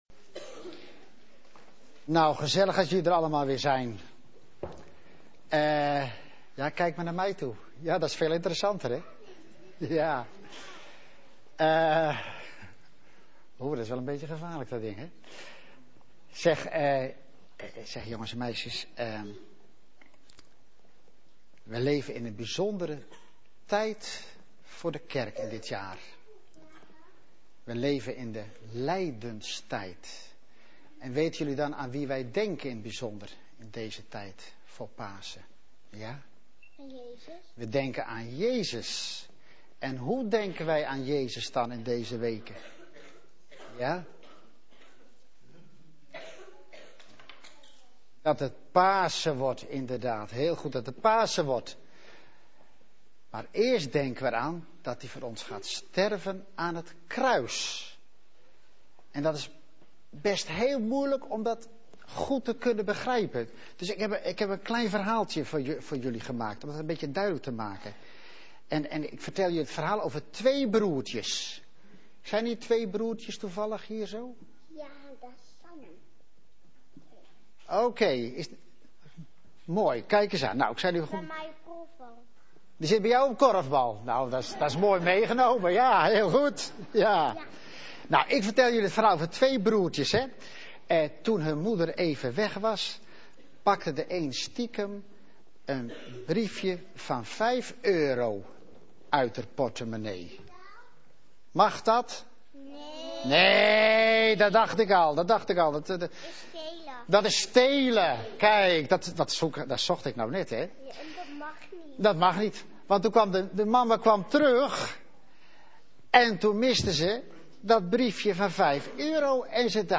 in de dienst van 4 Maart jl, zo'n leuk gesprekje had met de kinderen. Hierin probeerde hij iets duidelijk te maken over het plaatsvervangend lijden van Jezus.